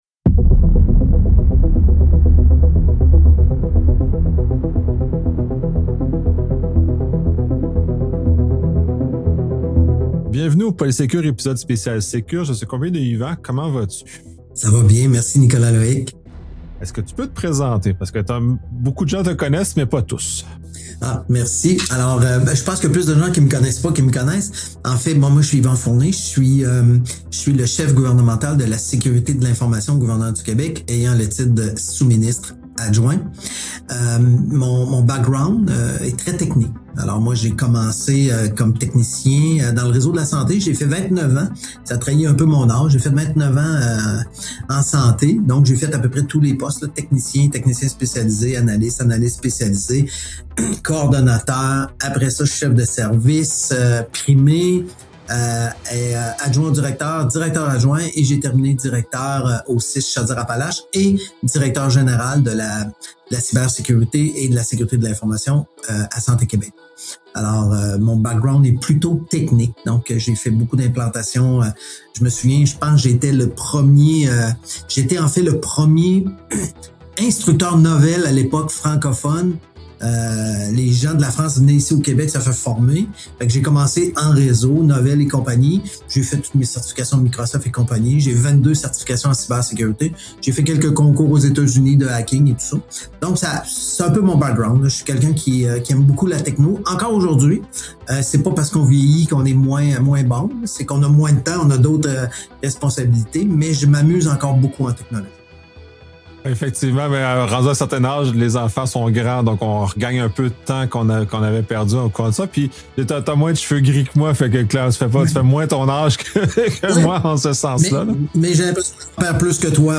Dans cet épisode du podcast, je reçois Yvan Fournier, chef gouvernemental de la sécurité de l’information du gouvernement du Québec, qui occupe le poste de sous-ministre adjoint. Cette conversation révèle l’ampleur des transformations en cours au sein de l’appareil gouvernemental québécois en matière de cybersécurité.